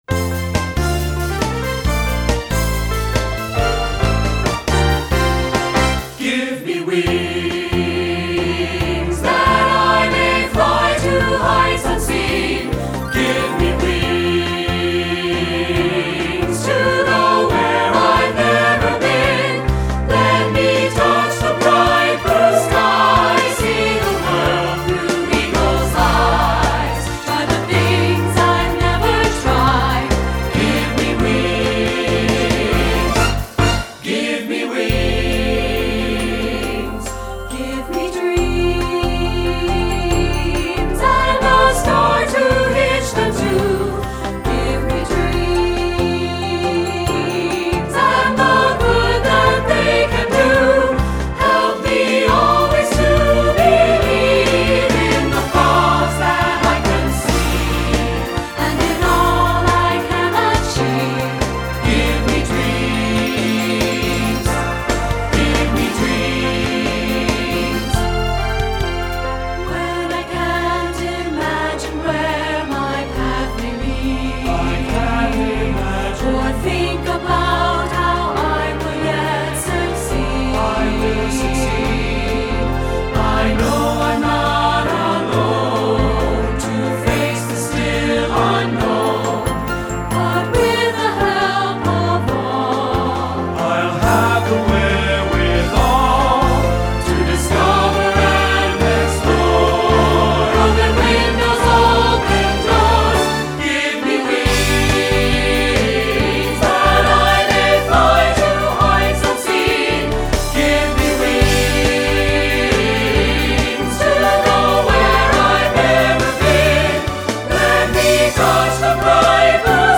secular choral